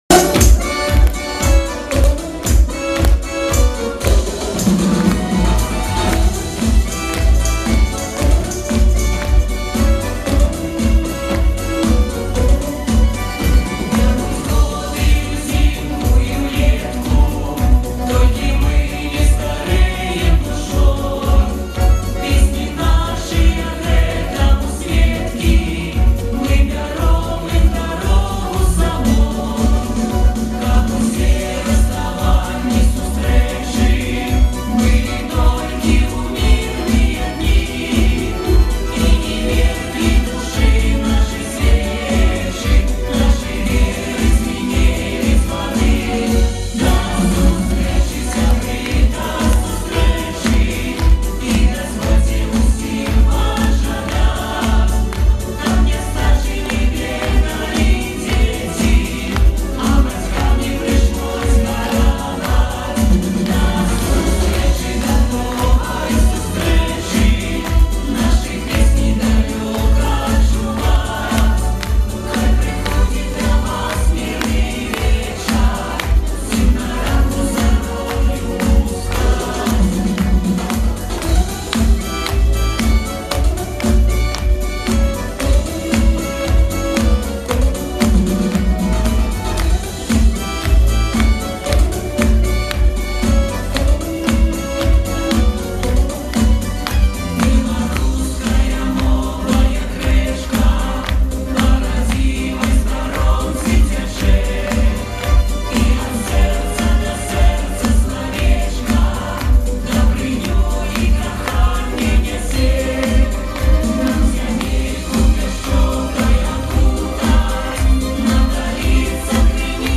Всі мінусовки жанру Pop-Folk
Плюсовий запис
Беларусские песни